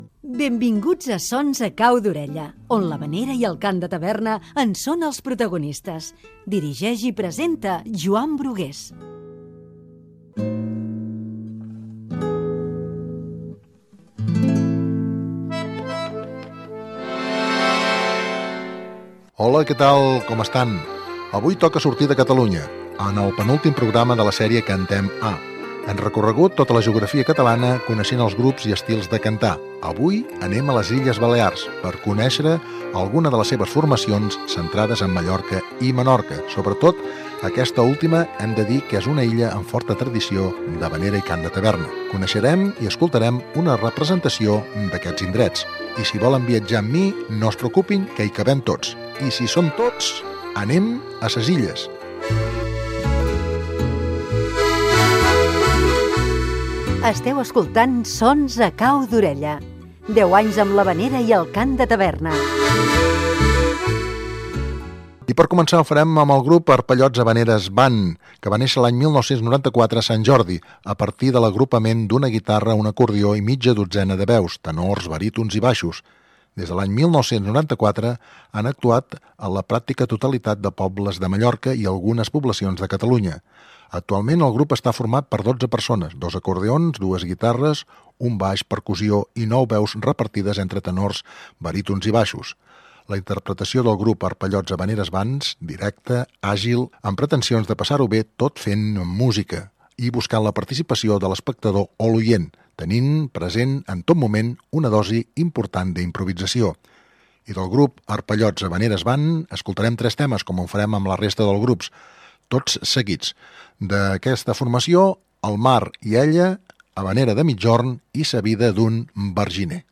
Careta del programa i presentació de l'espai dedicat a la música de taverna de les Illes Balears, indicatiu i tema musical
Musical